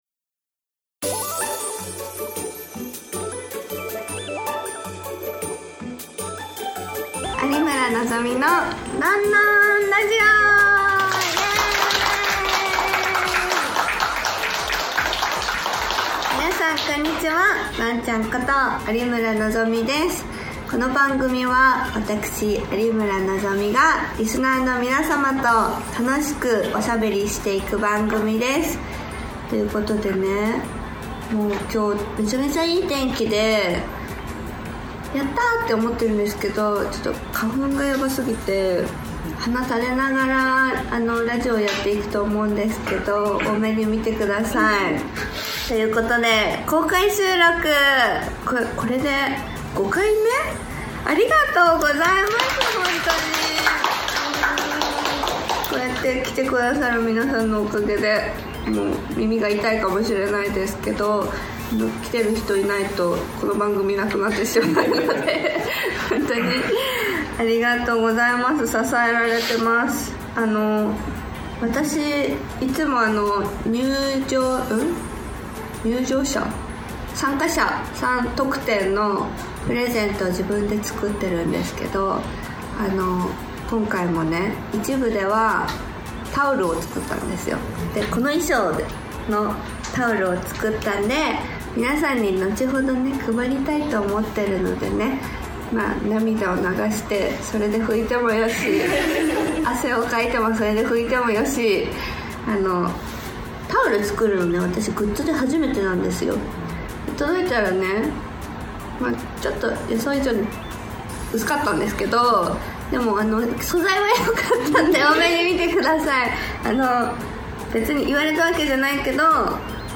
今年も公開収録が開催されました！